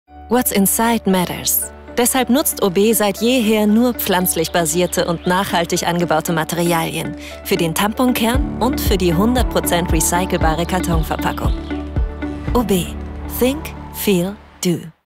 sehr variabel, hell, fein, zart
Werbung freundlich- warm - verbindlich - o.B.
Commercial (Werbung)